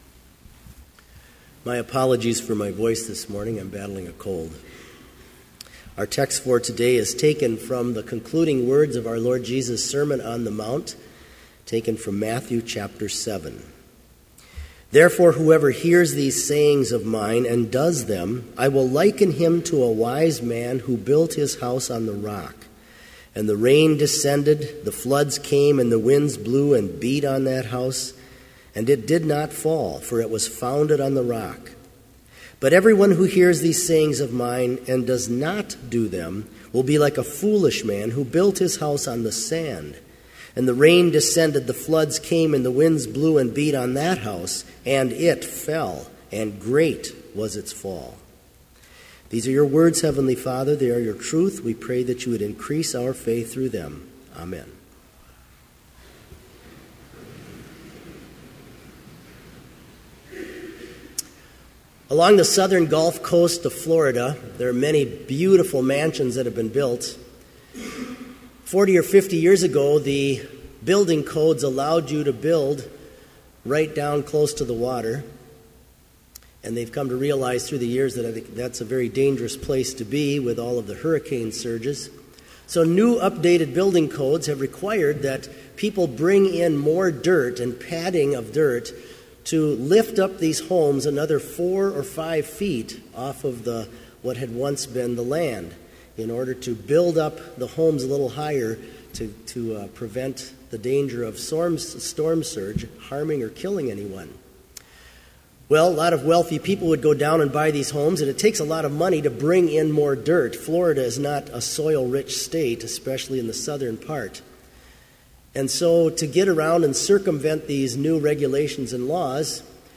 Complete Service
• Prelude
• Homily
This Chapel Service was held in Trinity Chapel at Bethany Lutheran College on Friday, January 23, 2015, at 10 a.m. Page and hymn numbers are from the Evangelical Lutheran Hymnary.